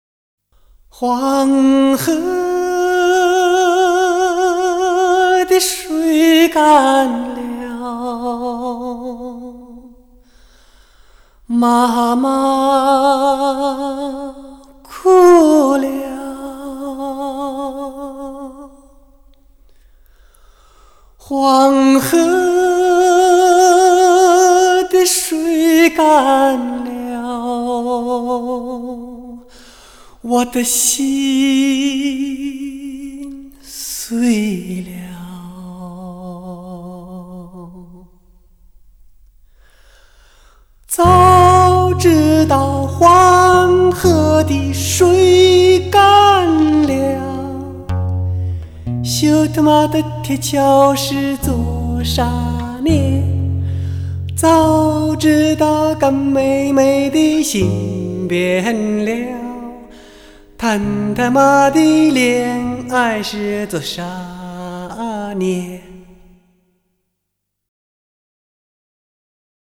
汽车音乐指定专用CD，3D音效+360°环绕，营造车内空间音乐天堂。
车载专业试音，第一天碟，催人心肺的爆棚力度，超强震撼。。。
极品清唱